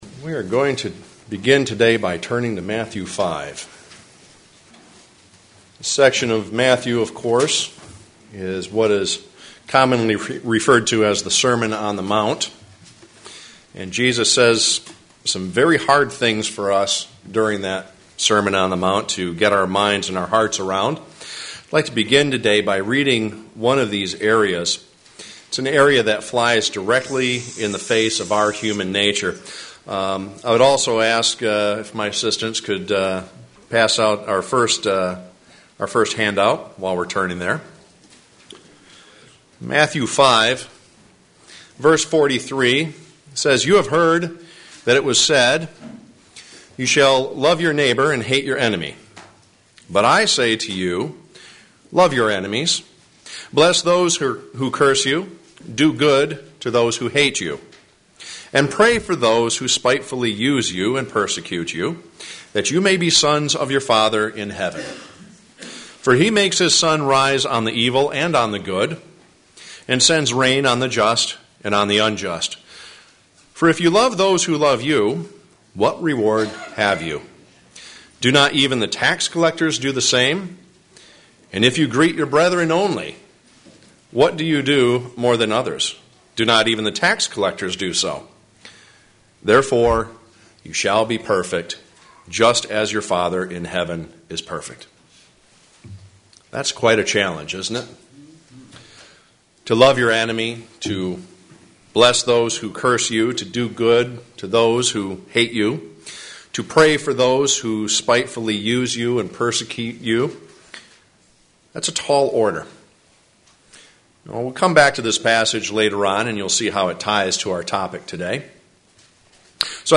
Stay tuned and find out. sermon Studying the bible?